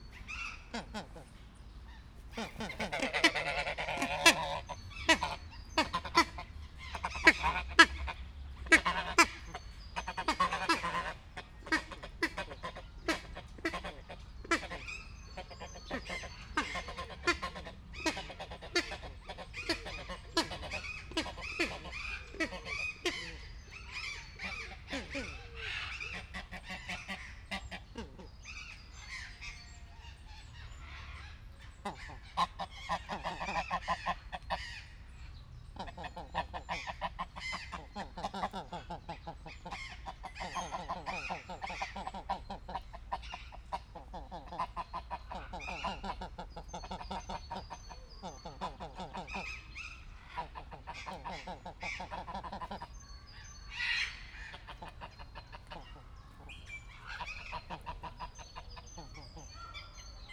Directory Listing of /_MP3/allathangok/debrecenizoo2019_professzionalis/magellan_lud/
mozgasbanlevoallatok0100.WAV